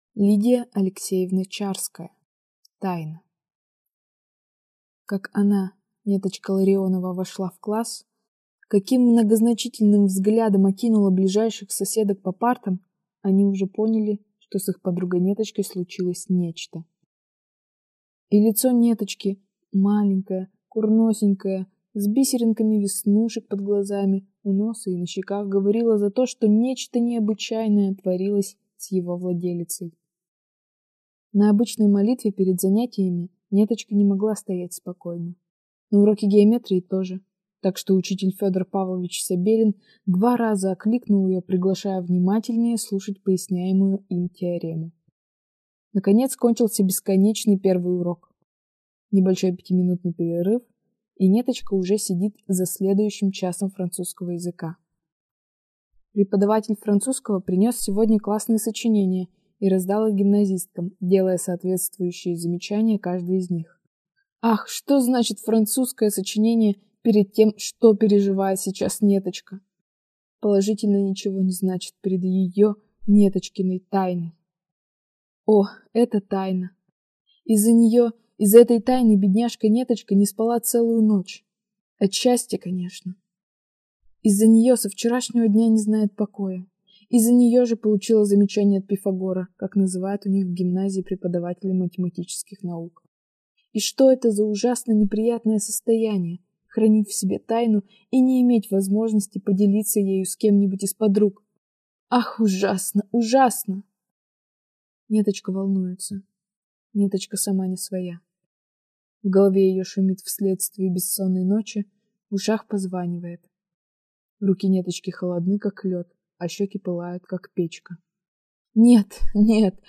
Аудиокнига Тайна | Библиотека аудиокниг